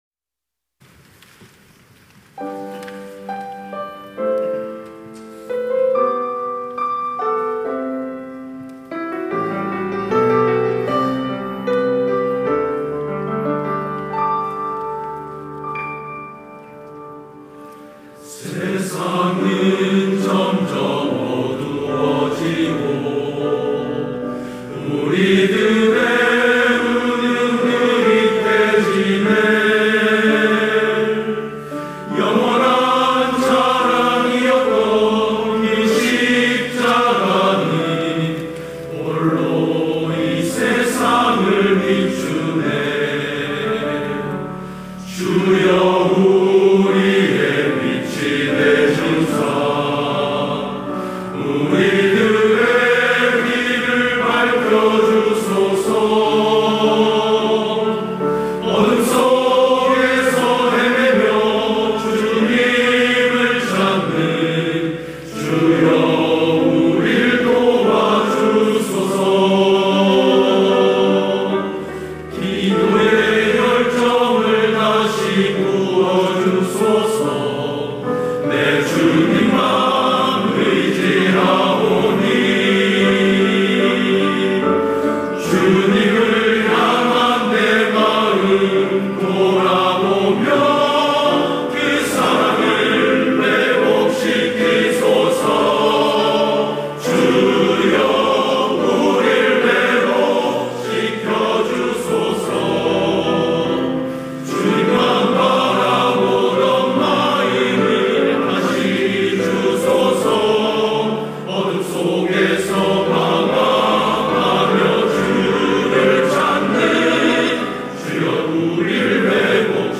찬양대 남선교회